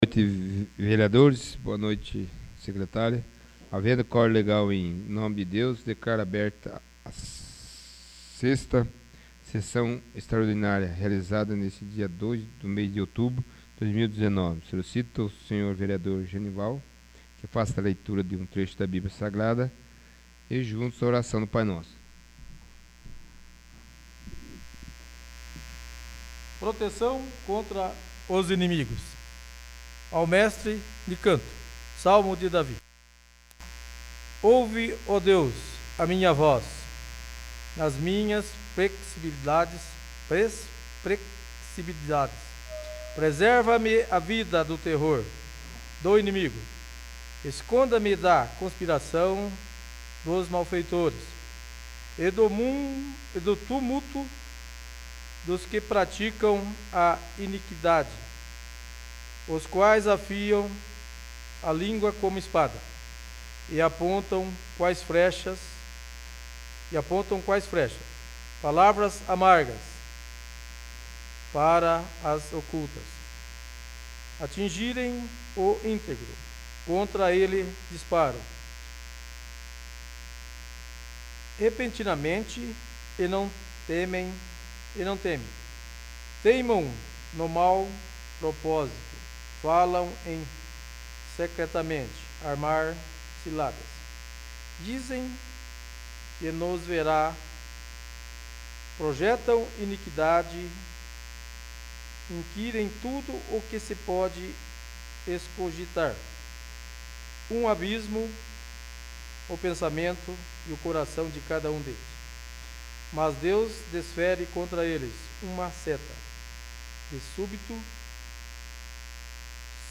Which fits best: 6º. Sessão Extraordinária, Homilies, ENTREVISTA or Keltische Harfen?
6º. Sessão Extraordinária